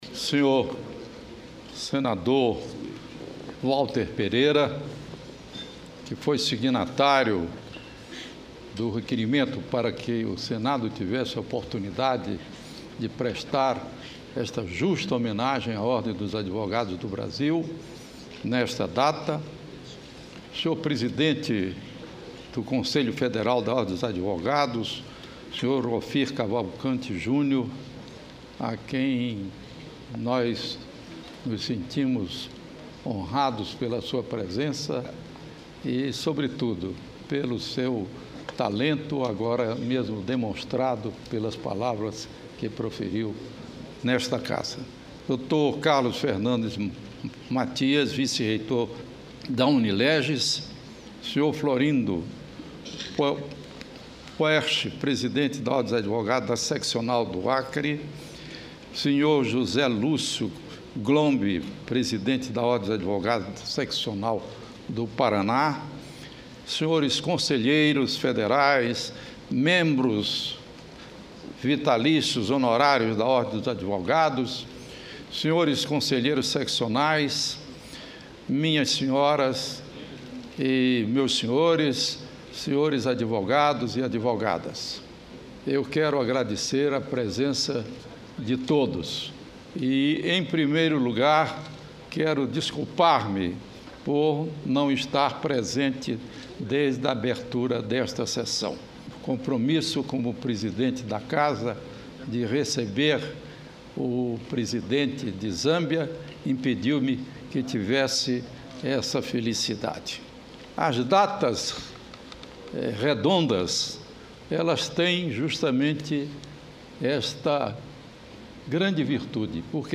Discurso do senador José Sarney